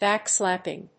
音節báck・slàp・ping 発音記号・読み方
/ˈbæˌkslæpɪŋ(米国英語)/